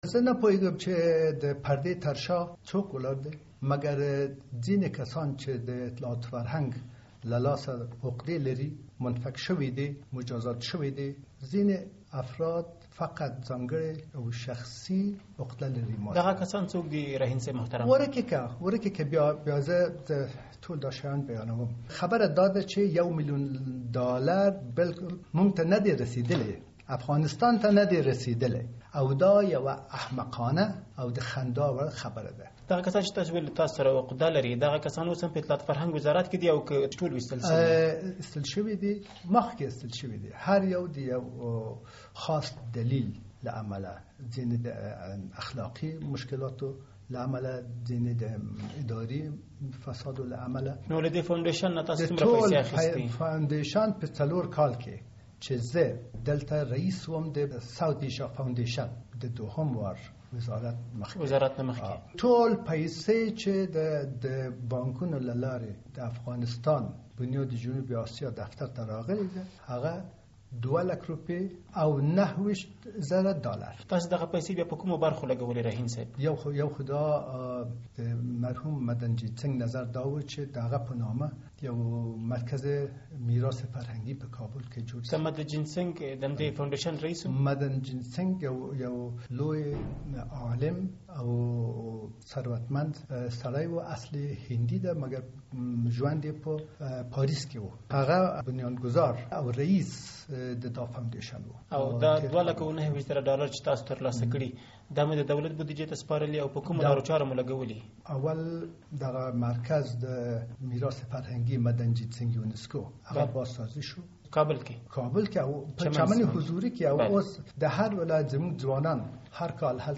له سید مخدوم رهین سره مرکه